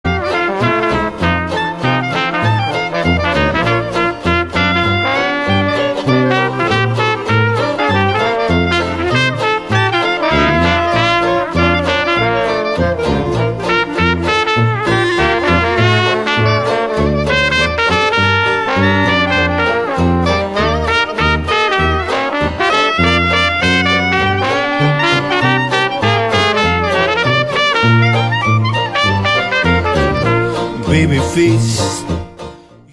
Jazz, Swing Bands
Dixieland jazz band plays happy foot-tapping, Trad Jazz.